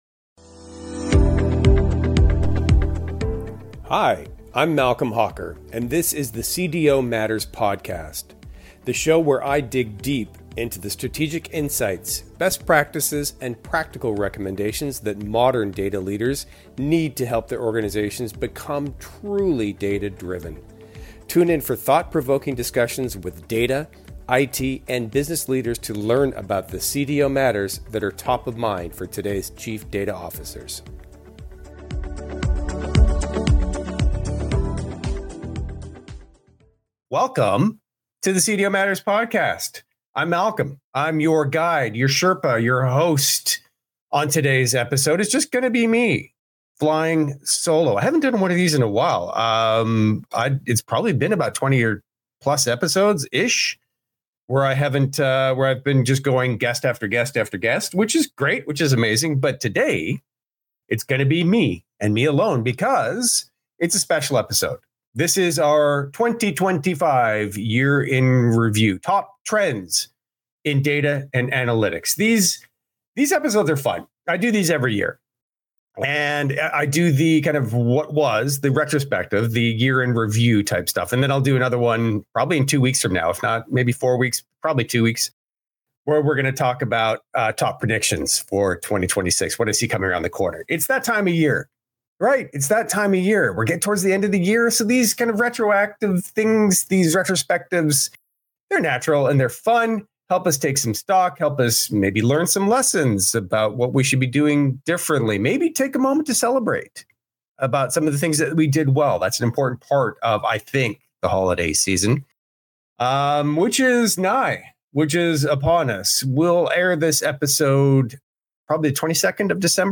I recorded interviews at Devoxx and … … continue reading